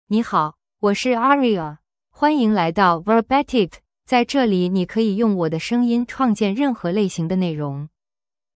Aria — Female Chinese (Mandarin, Simplified) AI Voice | TTS, Voice Cloning & Video | Verbatik AI
AriaFemale Chinese AI voice
Voice sample
Listen to Aria's female Chinese voice.
Female
Aria delivers clear pronunciation with authentic Mandarin, Simplified Chinese intonation, making your content sound professionally produced.